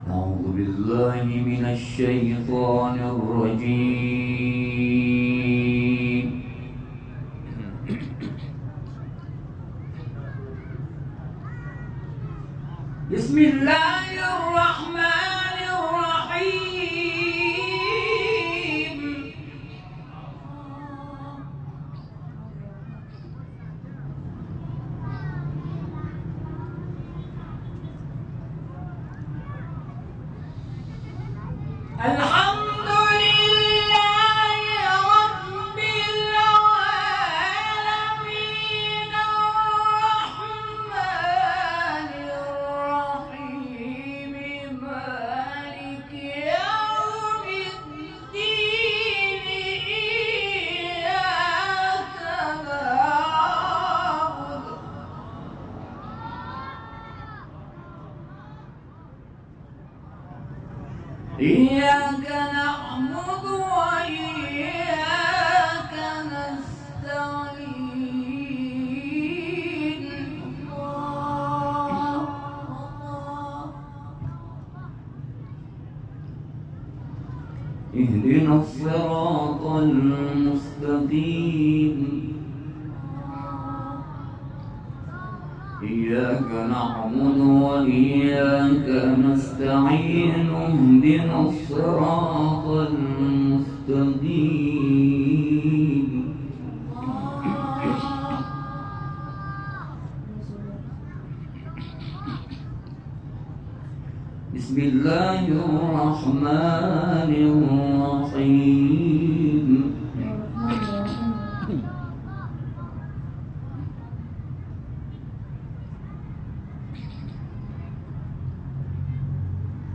صوت تلاوت سوره «فاتحه» و آیات ابتدایی سوره «بقره»
در محفل قرآنی چلچراغ هدایت